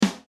(drums)
let snare = “